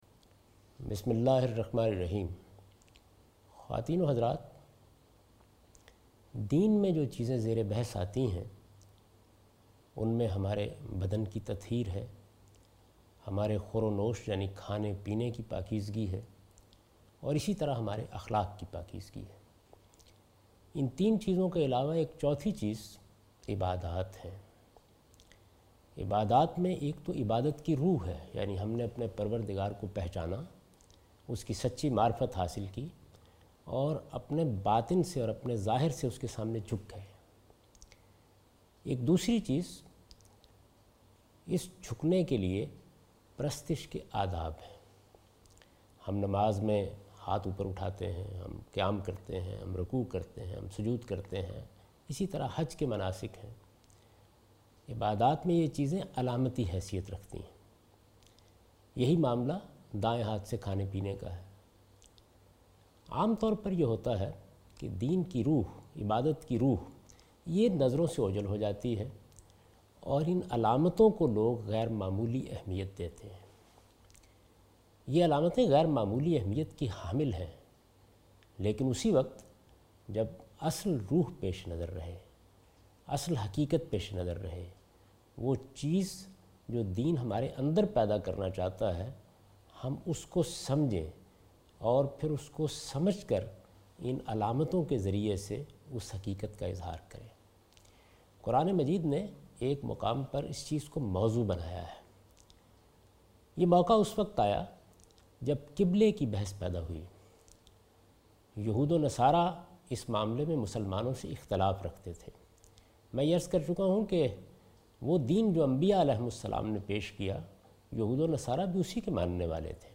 This series contains the lecture of Javed Ahmed Ghamidi delivered in Ramzan. He chose 30 different places from Quran to spread the message of Quran. In this lecture he discuss the soul purpose of prayers.